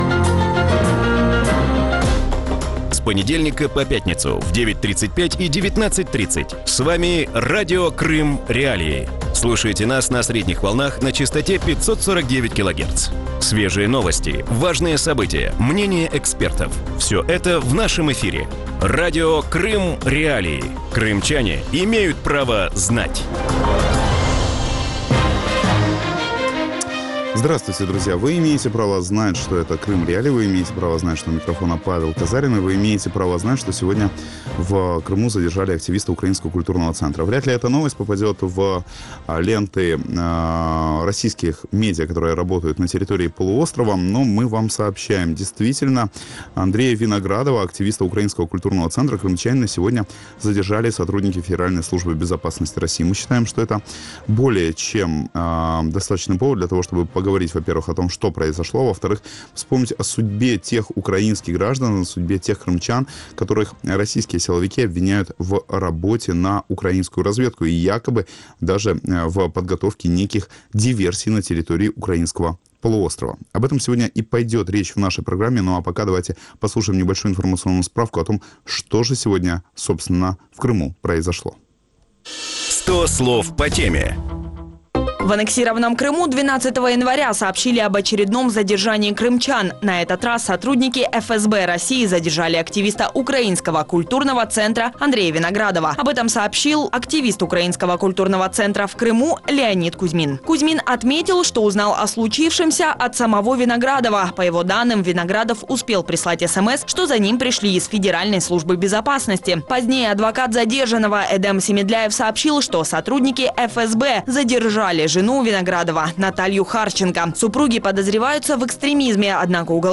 В вечернем эфире Радио Крым.Реалии говорят о задержании одного из активистов Украинского культурного центра в Крыму